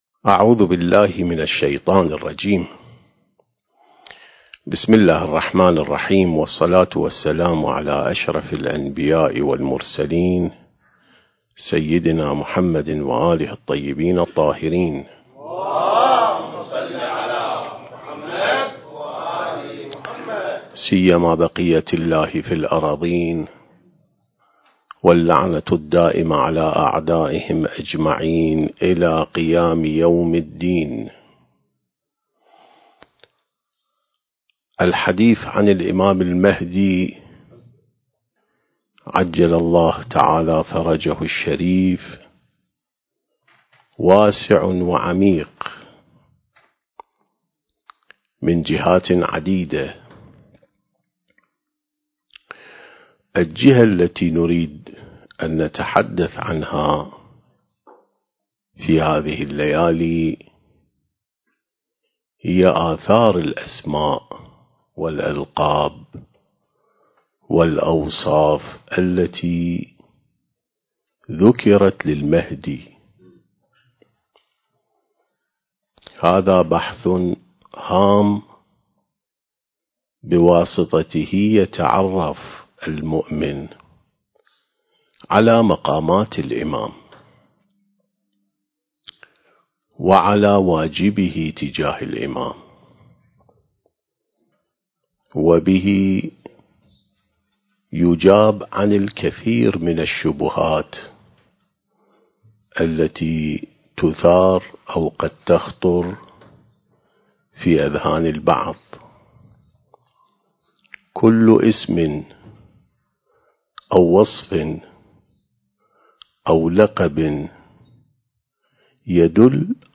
سلسلة محاضرات عين السماء ونهج الأنبياء (1) التاريخ: 1443 للهجرة